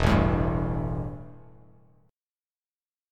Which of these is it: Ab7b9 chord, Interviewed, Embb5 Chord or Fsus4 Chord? Embb5 Chord